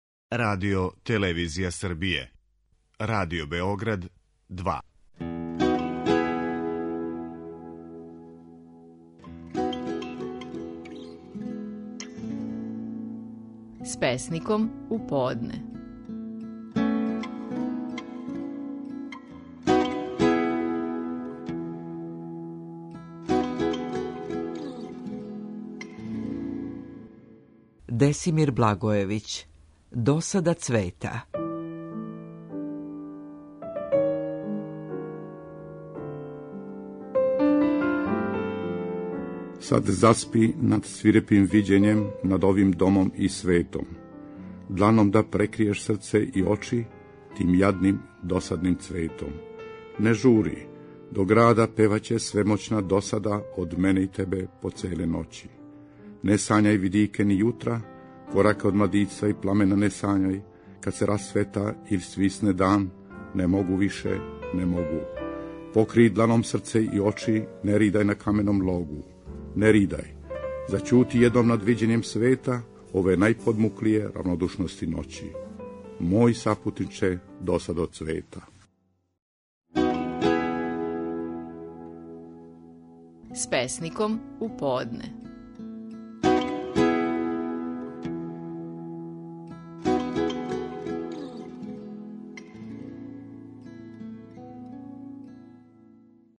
Стихови наших најпознатијих песника, у интерпретацији аутора.
У данашњој емисији слушамо како је стихове своје песме „Досада цвета" говорио Десимир Благојевић.